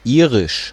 Ääntäminen
IPA : /ˈaɪrɪʃ/